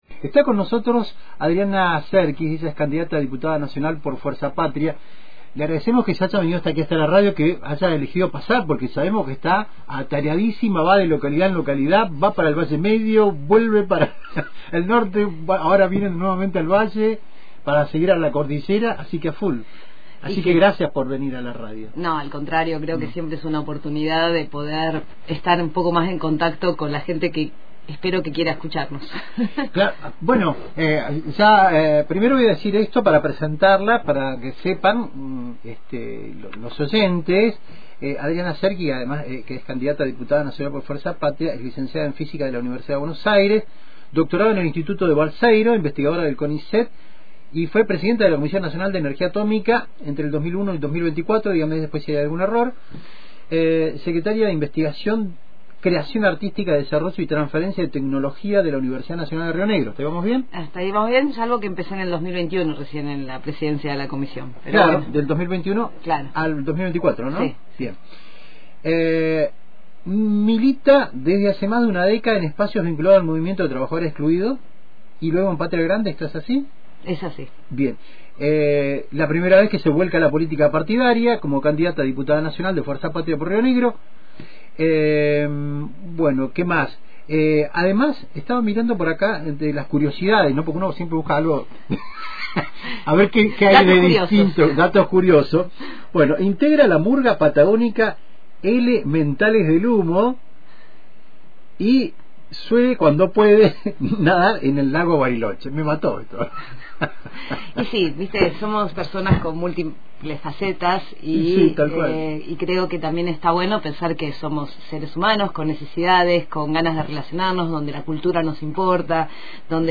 De visita por el estudio de Antena, destacó que su ingreso a la política responde a la necesidad de aportar soluciones colectivas y defender el conocimiento científico como herramienta de desarrollo. Subrayó la relevancia estratégica de la energía en la provincia y advirtió sobre los riesgos de la privatización de centrales nucleares. También planteó la urgencia de planificar una transición energética sostenible, con formación técnica para los jóvenes y agregado de valor a la producción local.